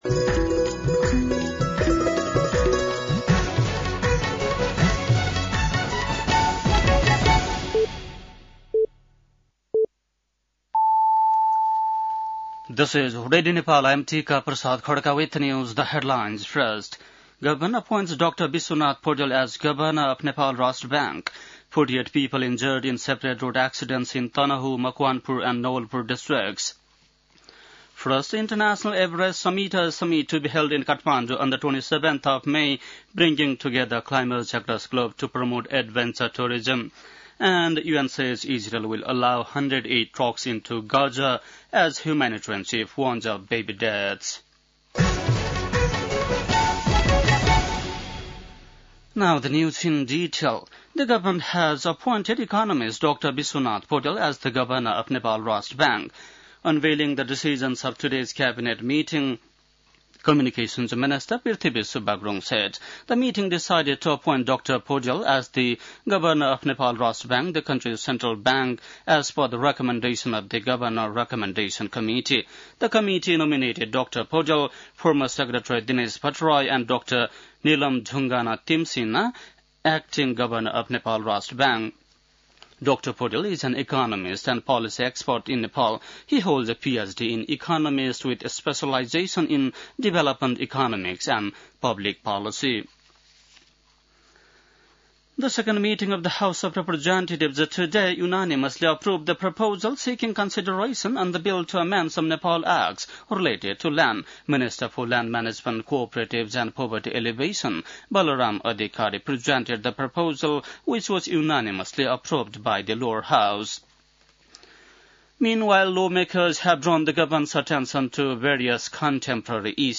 बेलुकी ८ बजेको अङ्ग्रेजी समाचार : ६ जेठ , २०८२